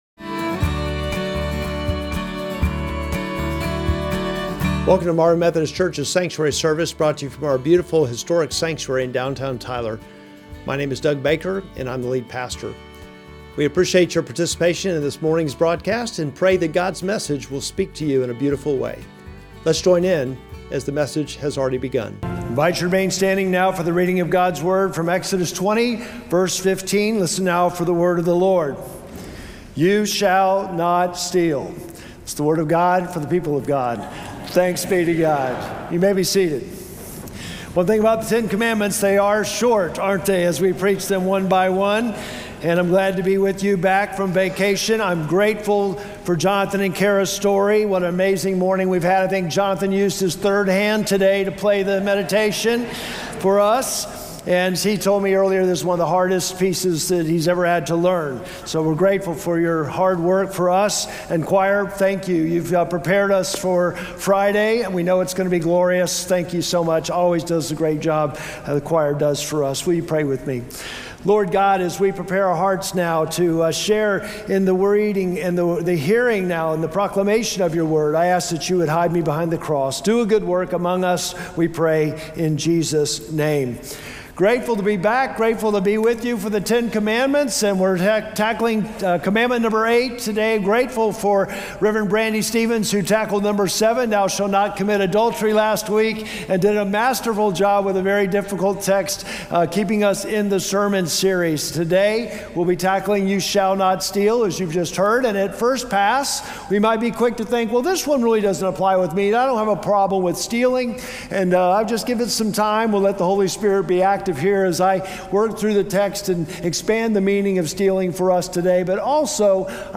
Sermon text: Exodus 20:15